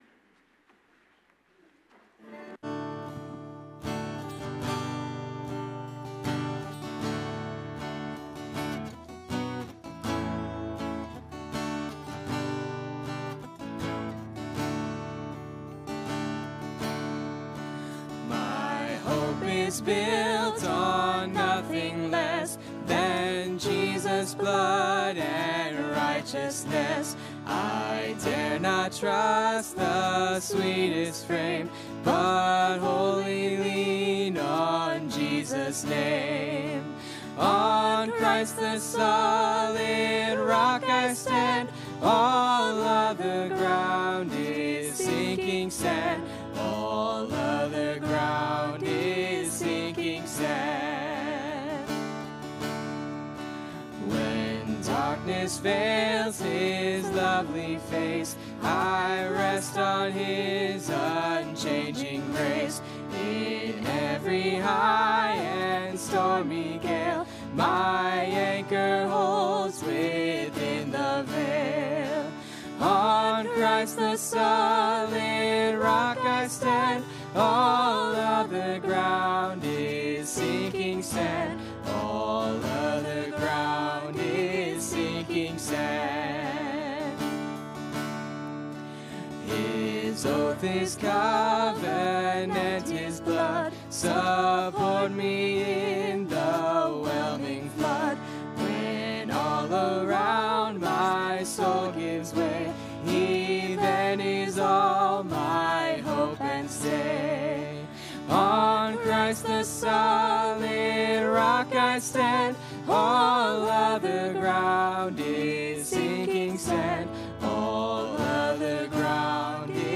Mark Passage: MARK 11:1-25 Service Type: Sunday Morning Click on title above to watch video.